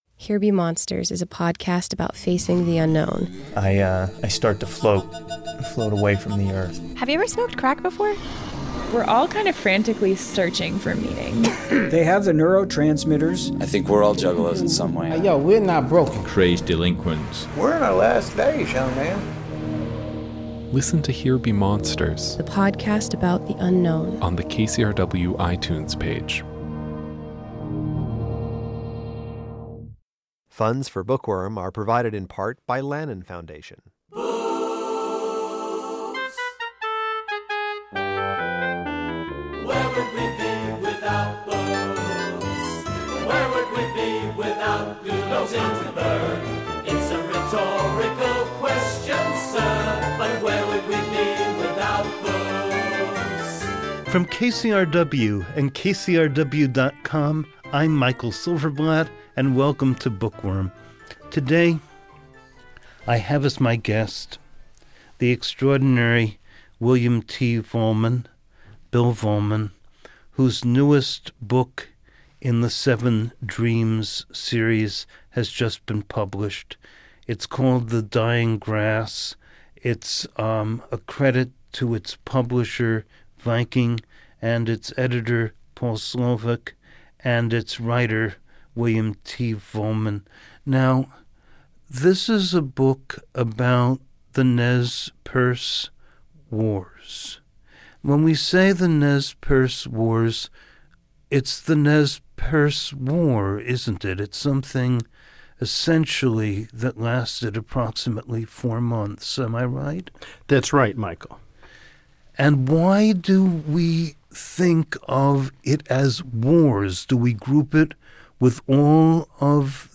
This is the first of two conversations about William Vollman’s novel of the 1877 war that destroyed the Nez Perce.